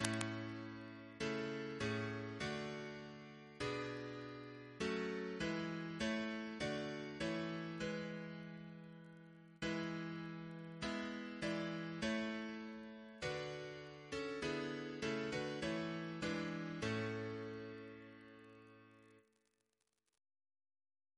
Double chant in A Composer: William Crotch (1775-1847), First Principal of the Royal Academy of Music Reference psalters: ACB: 51; ACP: 90; H1982: S430; OCB: 262; PP/SNCB: 65